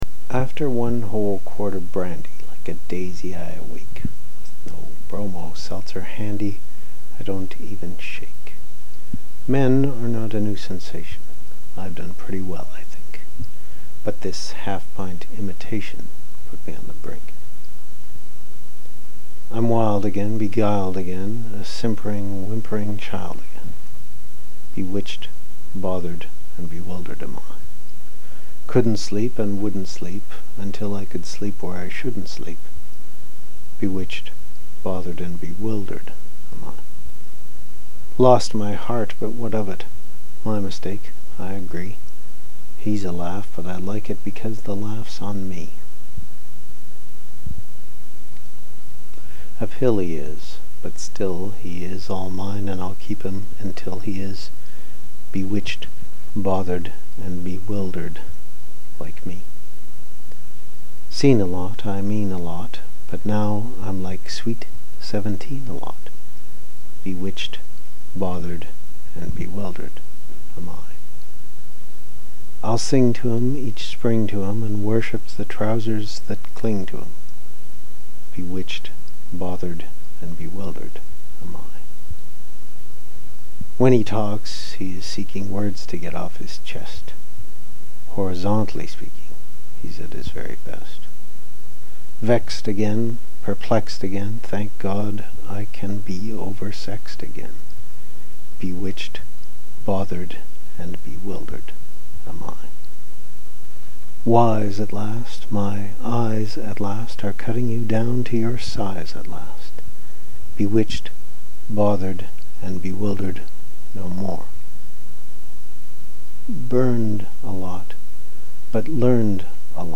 Each time some employee of this institution will read the lyrics to a Broadway show tune.